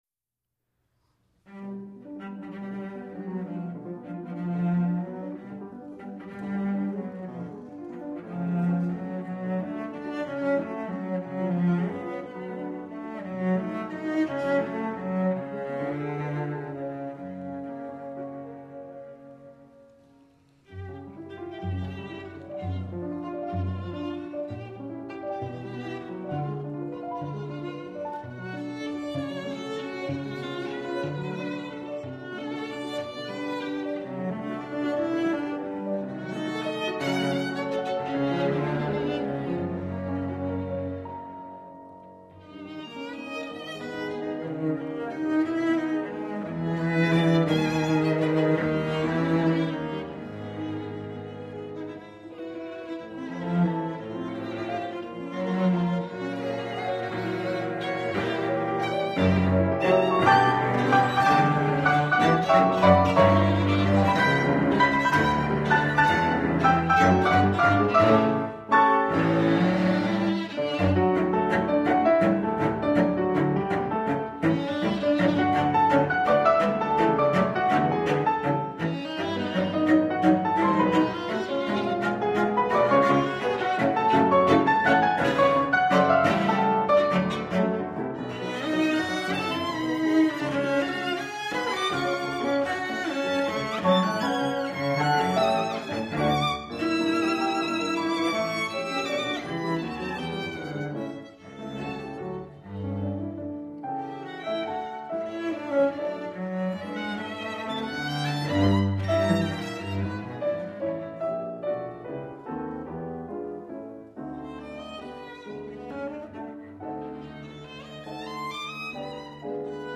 violin
cello
piano
Listen to the Swarthmore College Fetter Chamber Music Ensemble perform Johannes Brahms' Piano Trio No. 1 in B Major, Op. 8.